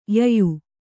♪ yayu